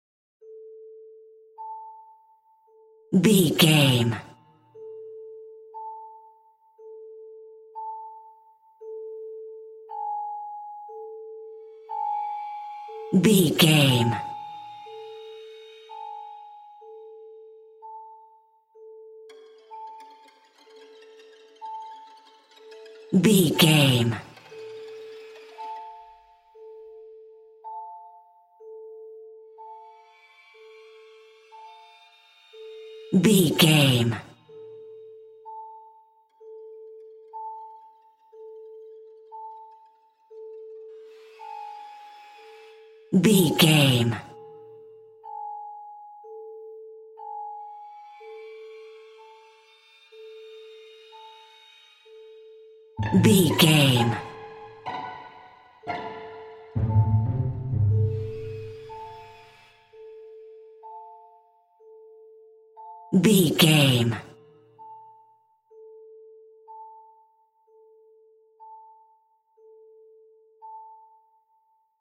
In-crescendo
Aeolian/Minor
ominous
dark
suspense
spooky
Synth Pads
Synth Strings
synth bass